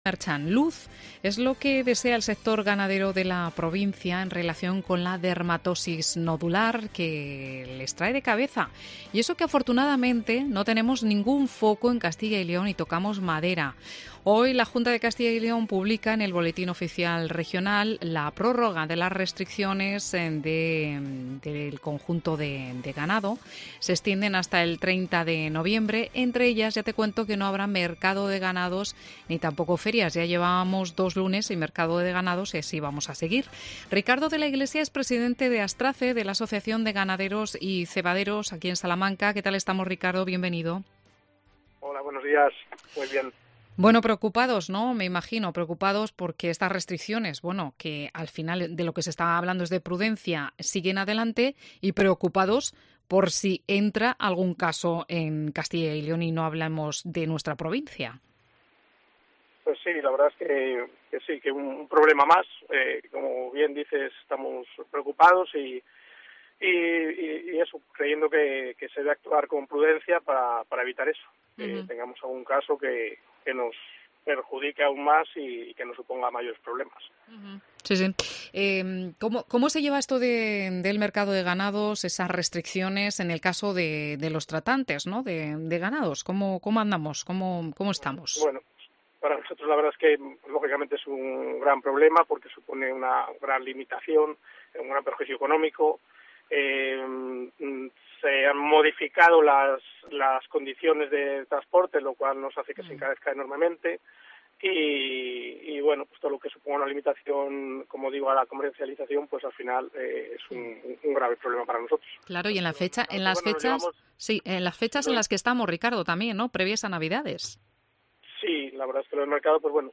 en una entrevista a COPE Salamanca